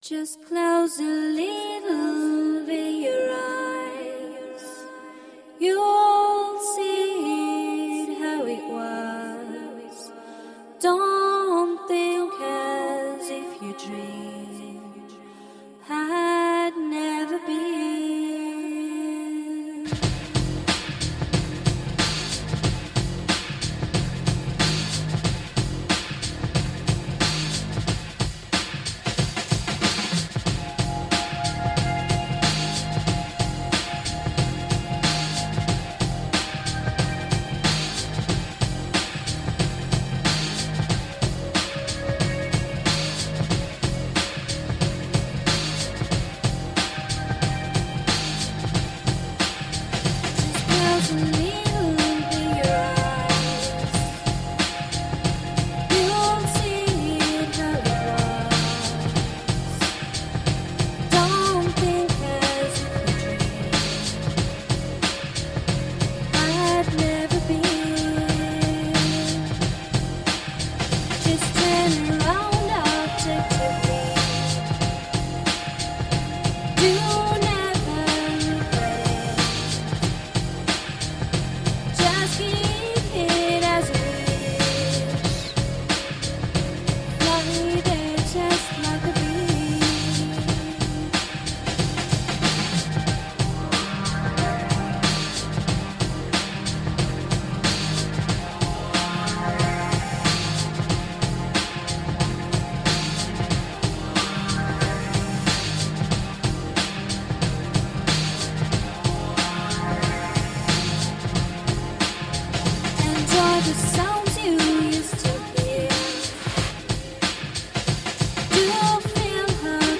Groovy, french downtempo electro-rock songs.